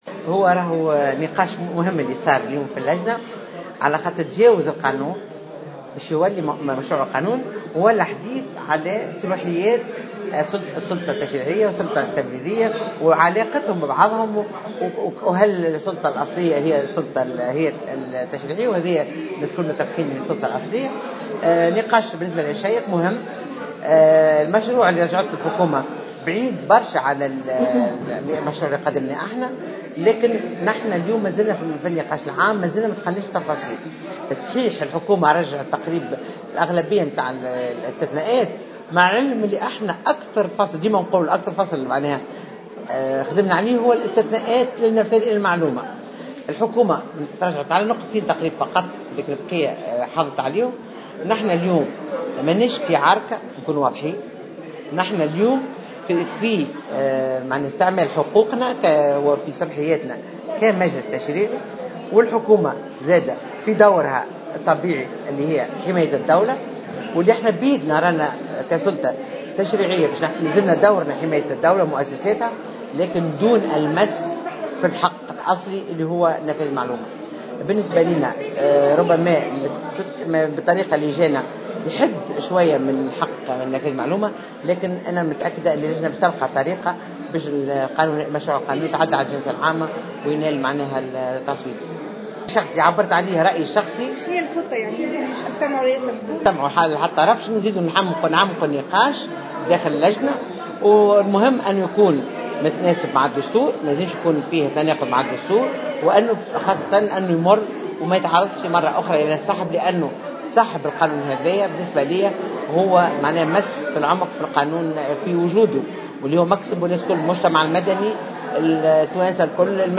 أكدت رئيس لجنة الحقوق والحريات بمجلس نواب الشعب بشرى بلحاج حميدة في تصريح للجوهرة "اف ام" اليوم الأربعاء أن النقاش الذي جرى اليوم بخصوص مشروع قانون النفاذ للمعلومة تجاوز القانون في حد ذاته ليصبح حديثا عن صلاحيات السلطة التشريعية والسلطة التنفيذية في علاقة ببعضهم.